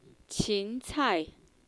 so even the male dialogue has female voice.